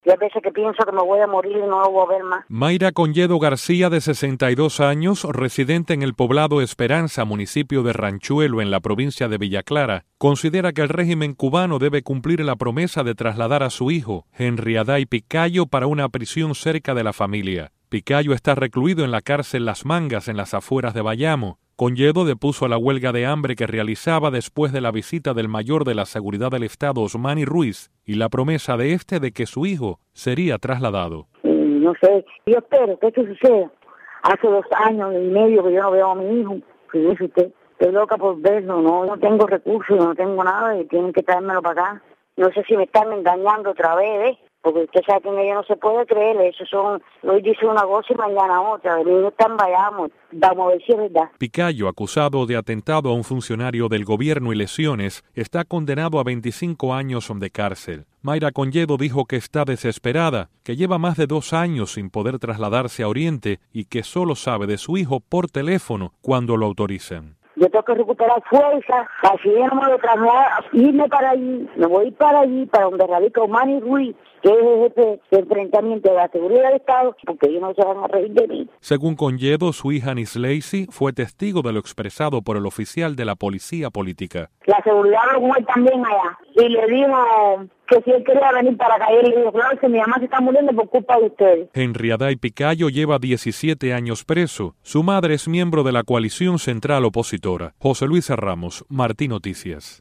Noticias de Radio Martí